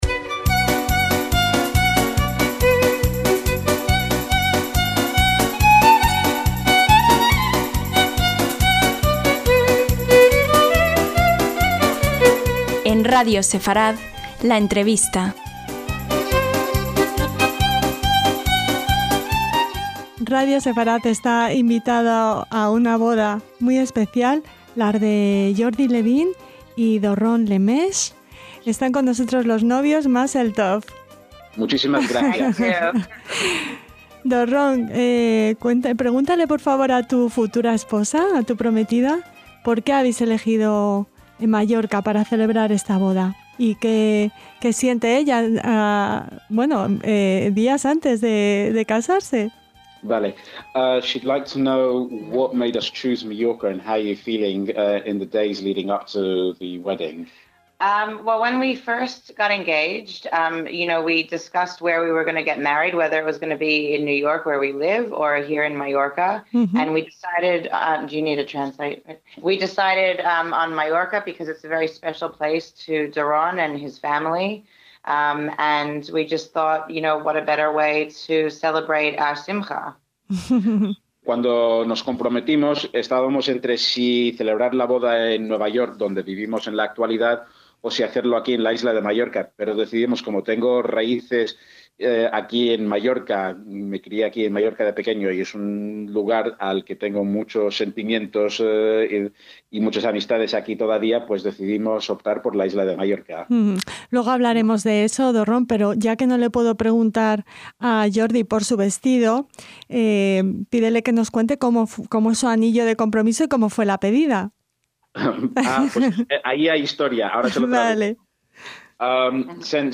LA ENTREVISTA - Sus abuelos se hicieron novios en un campo de refugiados en el que estaban internados tras la Shoá (en la que su abuela luchó como partisana).